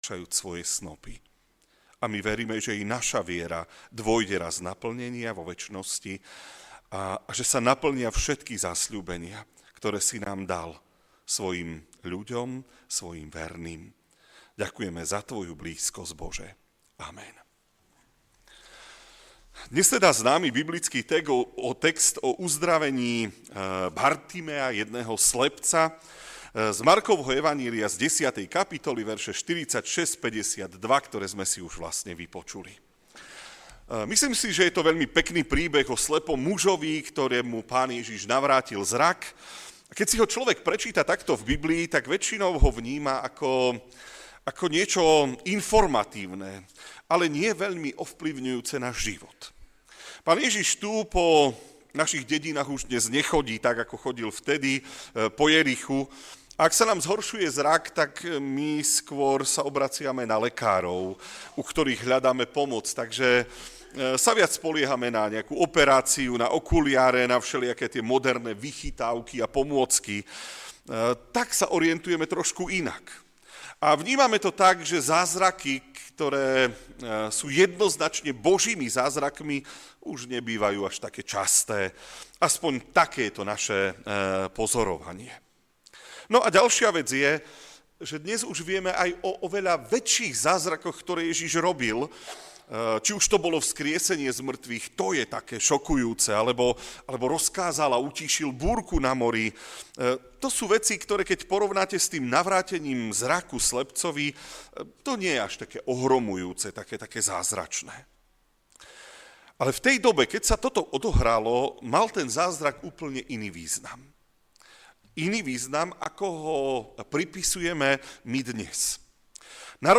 Podcast - kázne